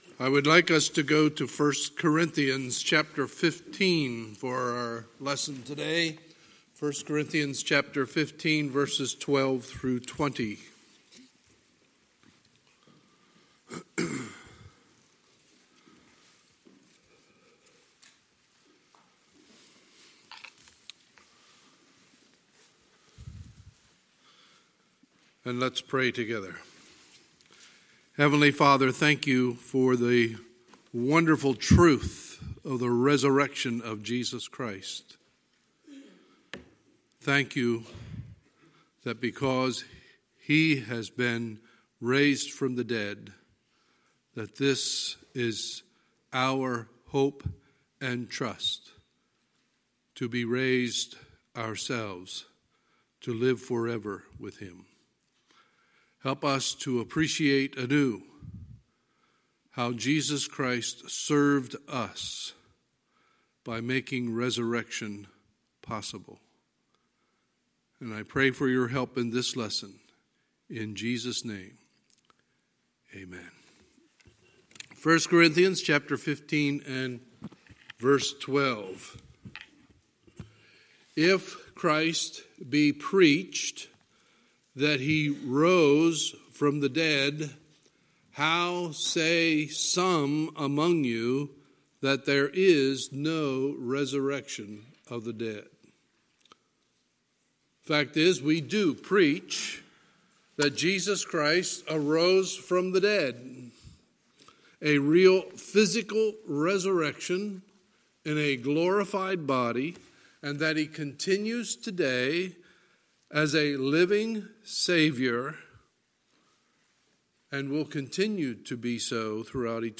Sunday, December 1, 2019 – Sunday Morning Service